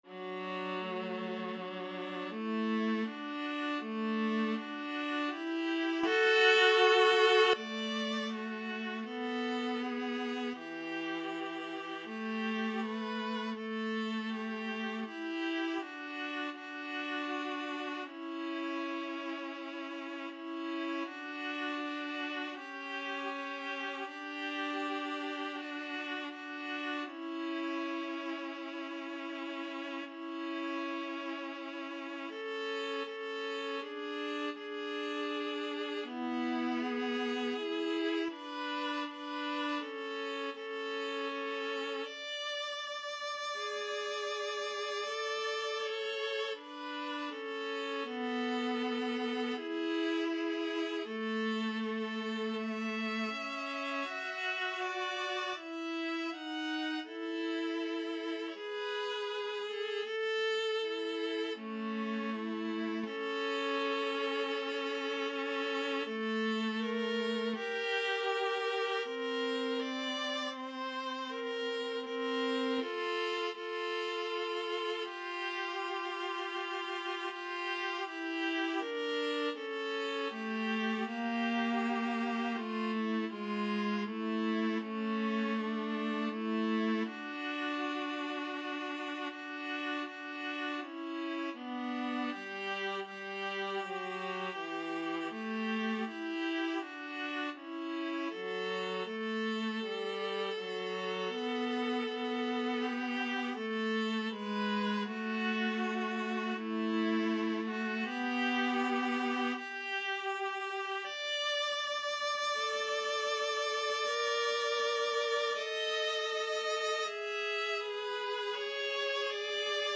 2/2 (View more 2/2 Music)
Adagio = c. 40
Classical (View more Classical Viola Duet Music)